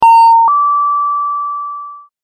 关于水被倾倒出声音的PPT模板_风云办公